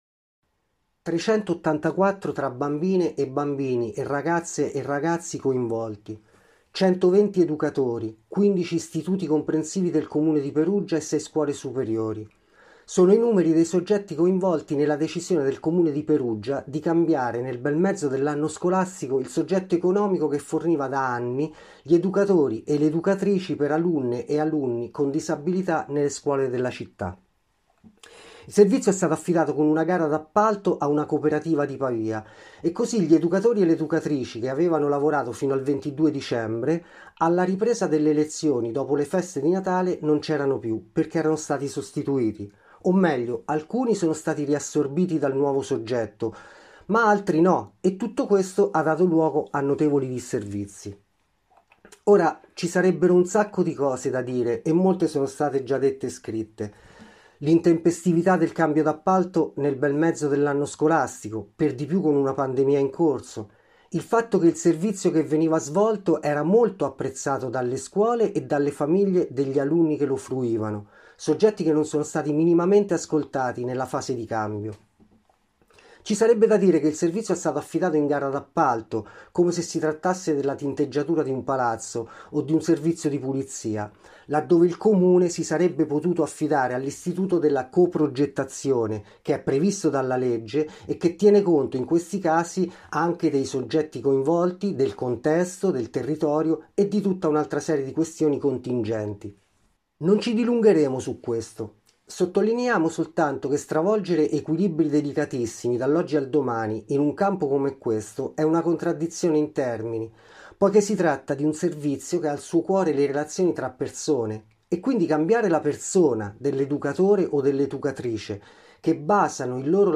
Abbiamo intervistato la portavoce di un gruppo di genitori decisi a far valere i loro diritti.